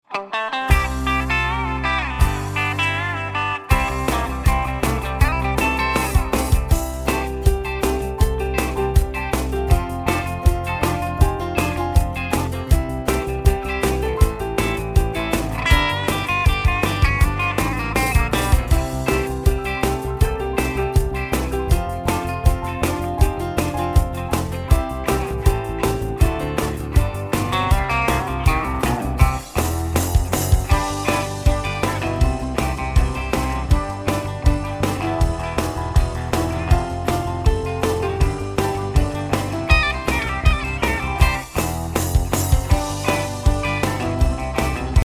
*  Catchy melodies, dumb jokes, interesting stories